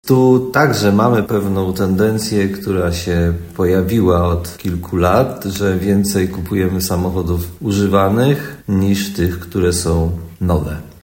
Mówi prezydent Tarnobrzega Dariusz Bożek.